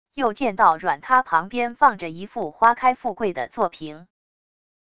又见到软塌旁边放着一副花开富贵的座屏wav音频生成系统WAV Audio Player